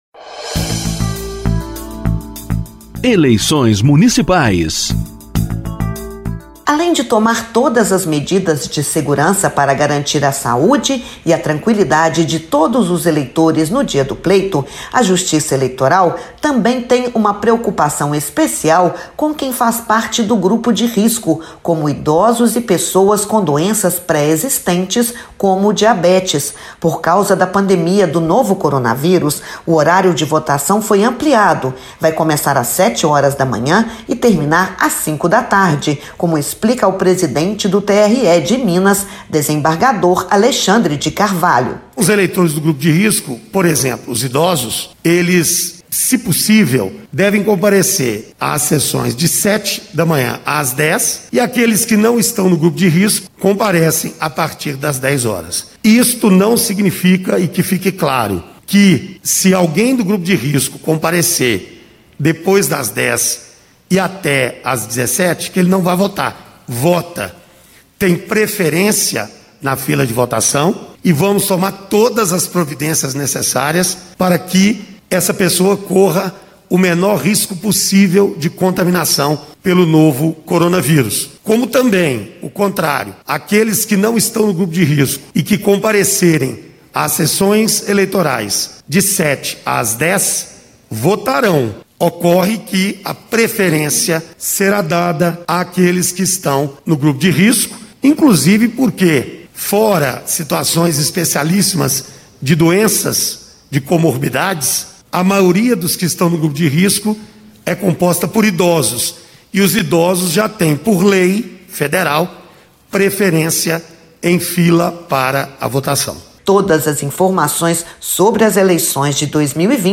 Ouça a explicação do presidente do Tribunal Regional Eleitoral em Minas, desembargador Alexandre de Carvalho, , sobre como proceder no dia da eleição.